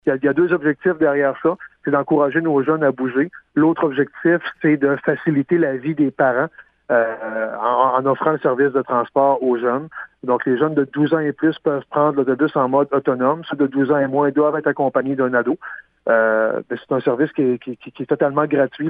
Le maire Daniel Côté donne plus de détails.